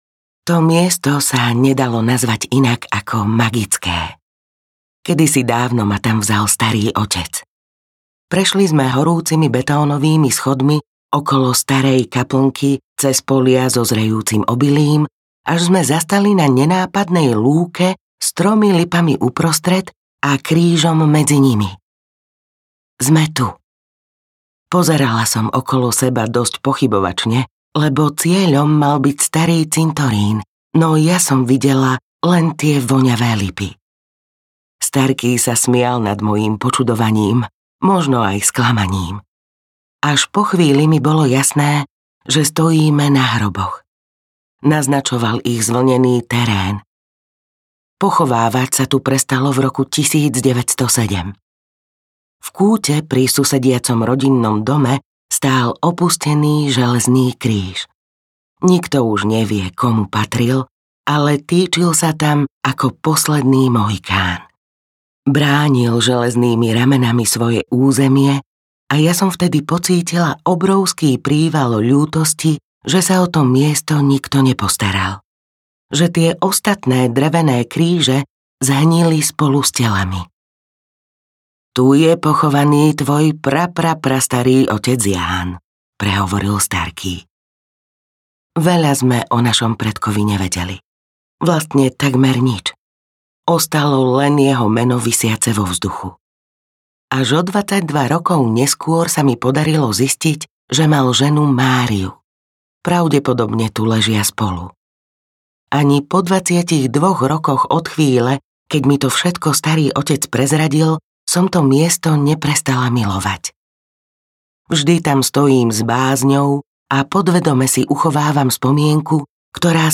Kde si sa vydala, tam si mlieko pýtaj audiokniha
Ukázka z knihy